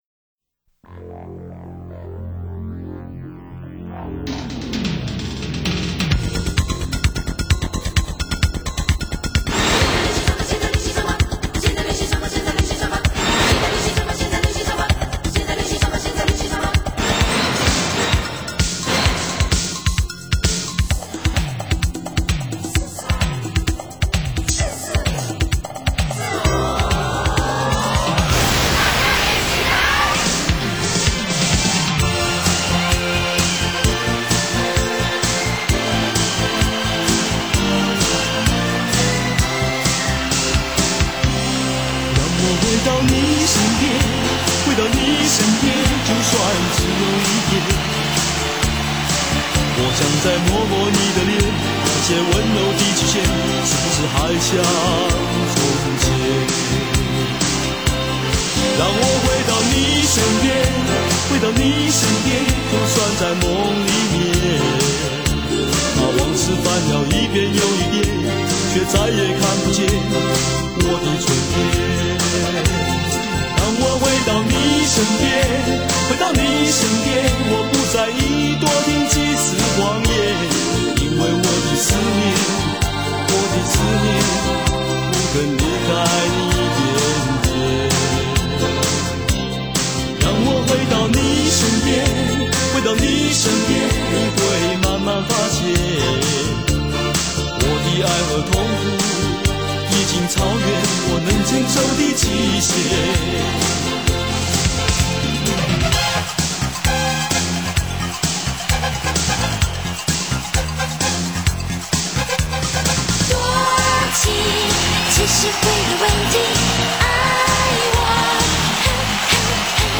采45轉快轉的方式演唱串聯當紅歌曲的組曲